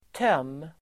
Uttal: [töm:]